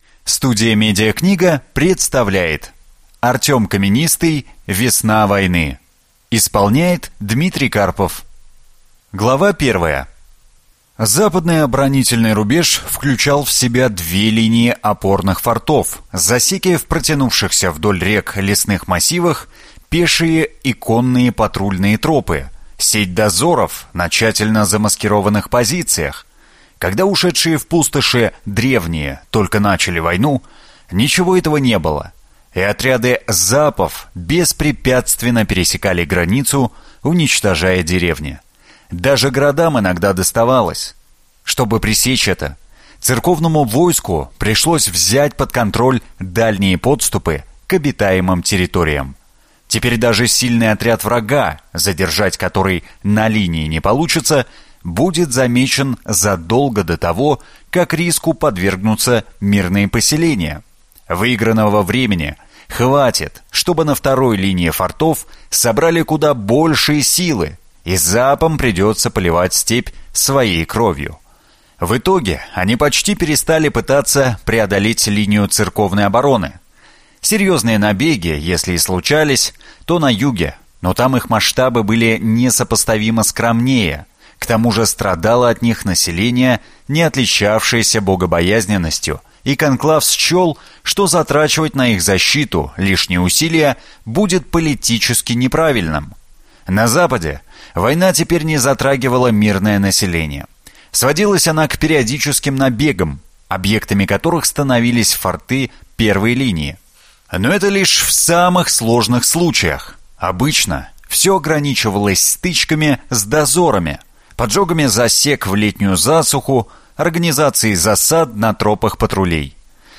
Аудиокнига Весна войны - купить, скачать и слушать онлайн | КнигоПоиск